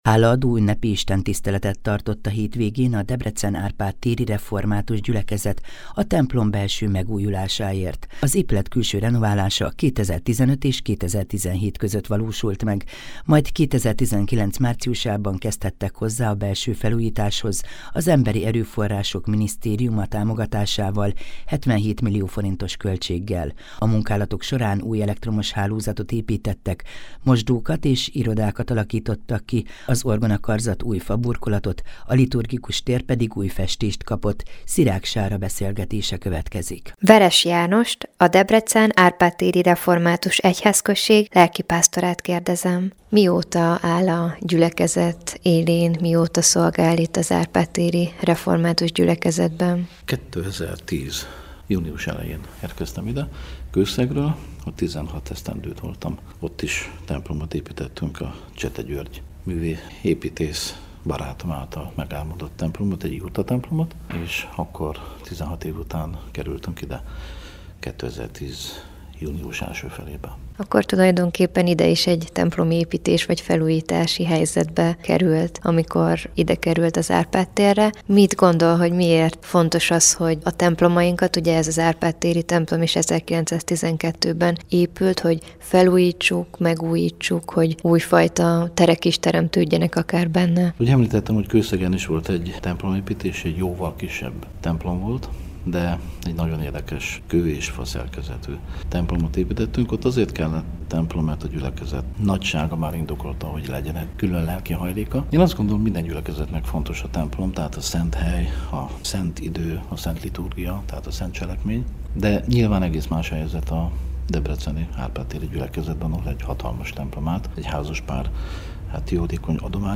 Hálaadó ünnepi istentiszteletet tartottak a Debrecen-Árpád téri templom belső megújulásáért